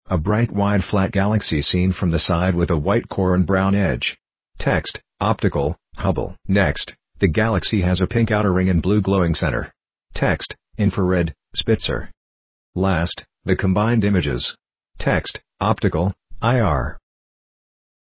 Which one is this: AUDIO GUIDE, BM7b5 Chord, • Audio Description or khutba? • Audio Description